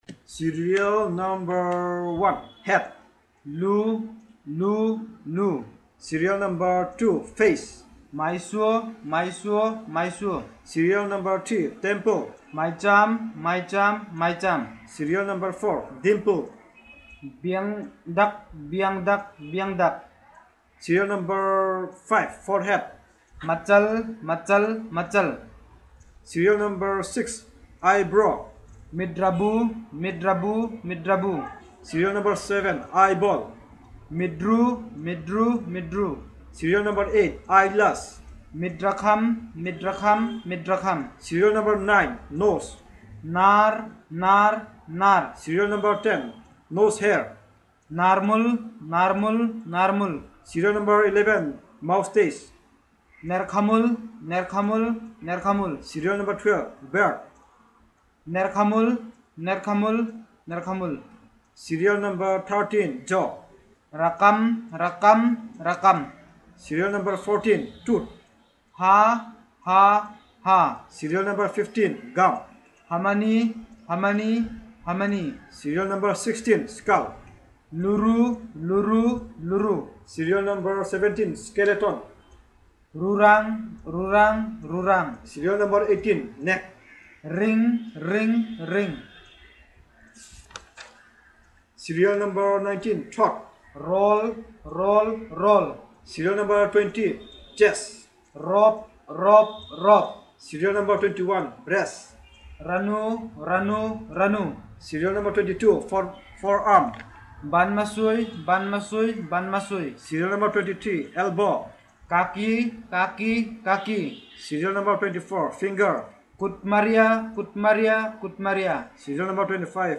Elicitation of words about human body parts.